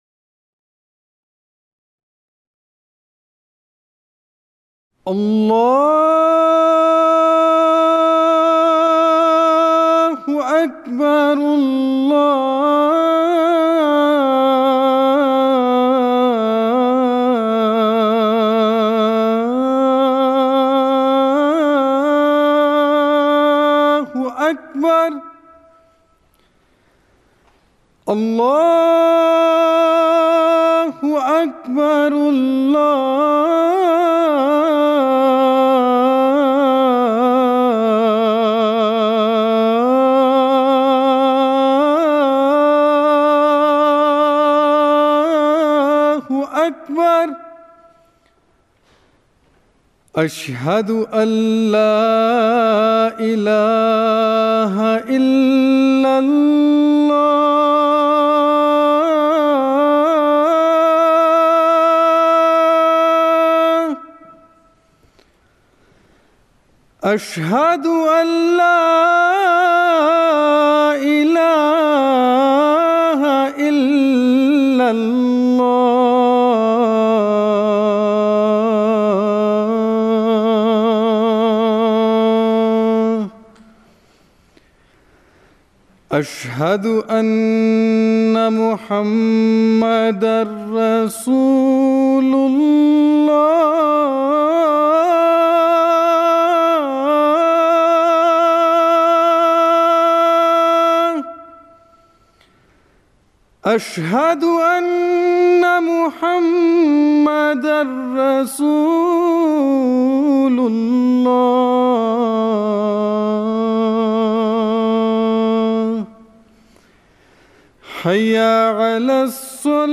اذان
Adhan / Azan